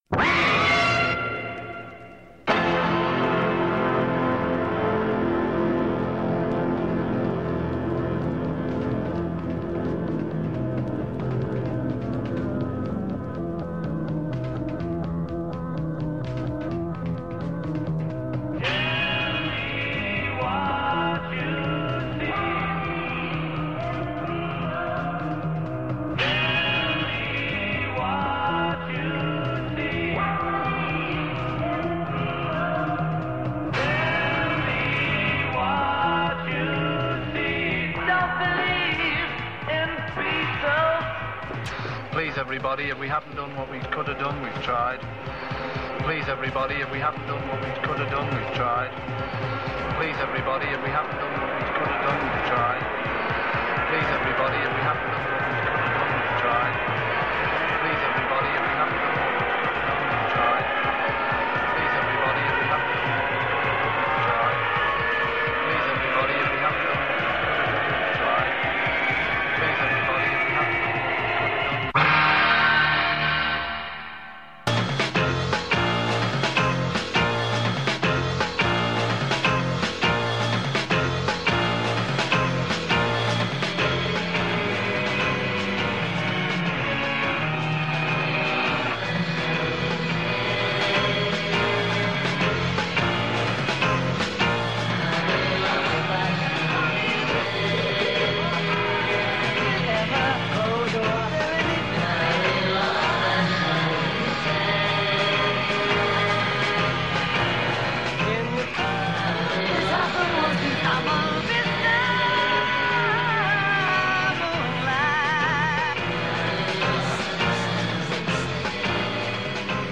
An office worker in Memphis, Tennessee plays music from his record collection, with a focus on post-punk, electronic music, dub, and disco.